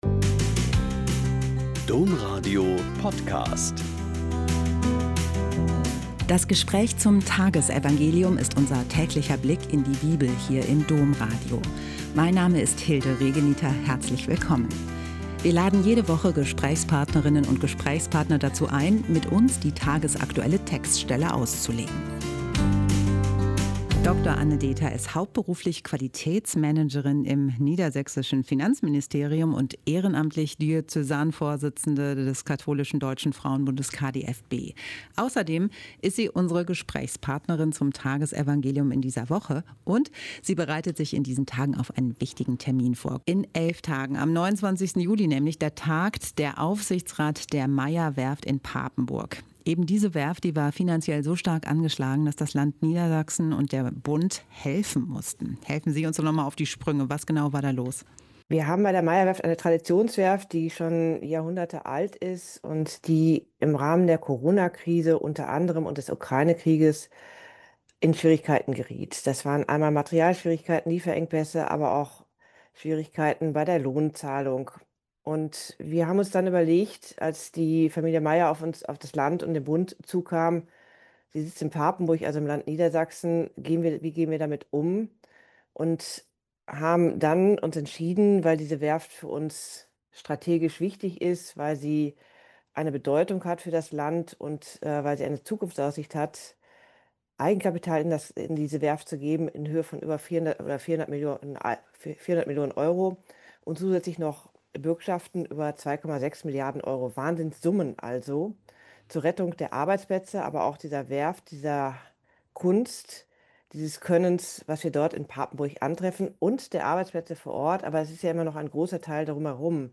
Mt 12, 1-8 - Gespräch